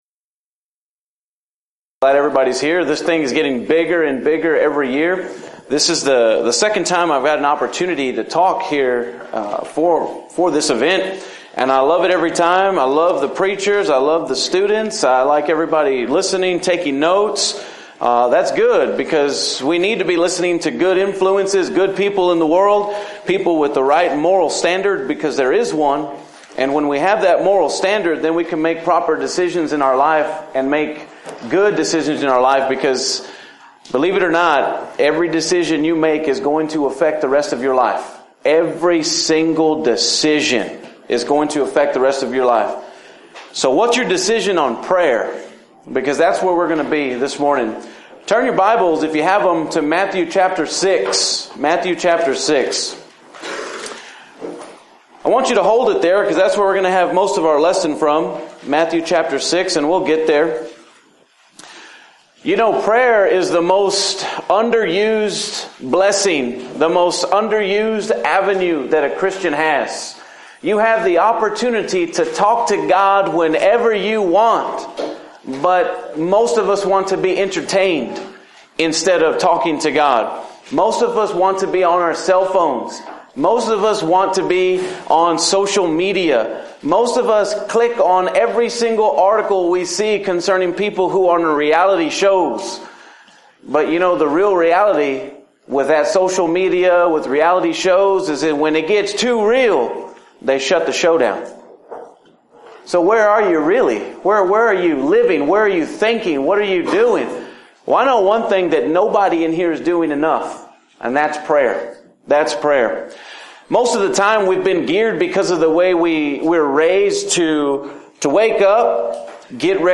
Youth Sessions